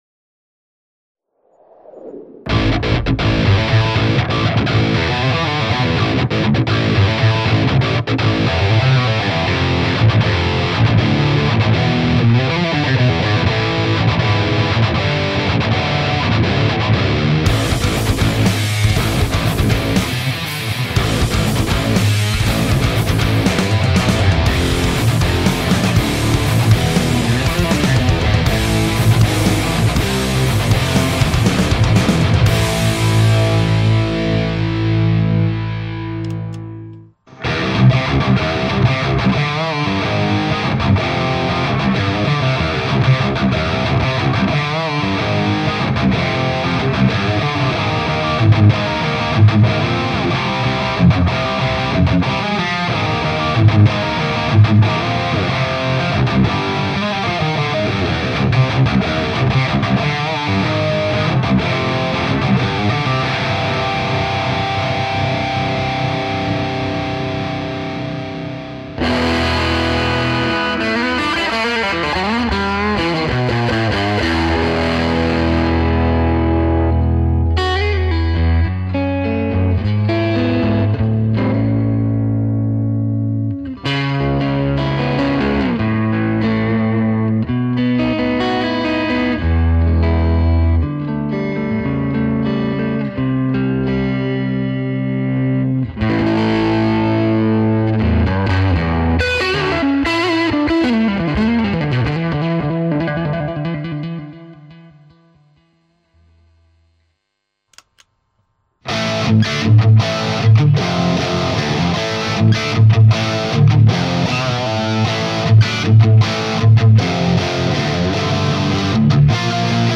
IK Multimedia ToneX Guitar Bass Multi Effects (Demo In Details) – Selectafex
TONEX Pedal provides a noise gate, analog-modeled EQ and compressor, and 5 stereo reverbs derived from AmpliTube X-SPACE.